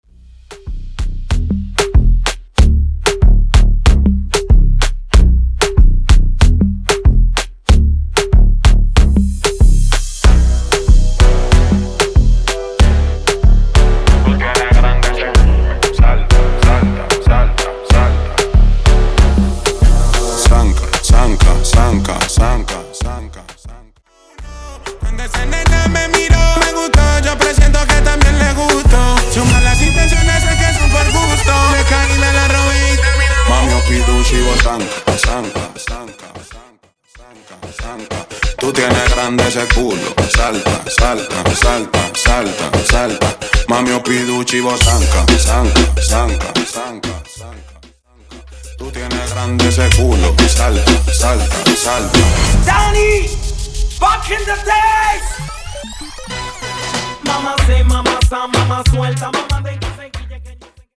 Mashup Dirty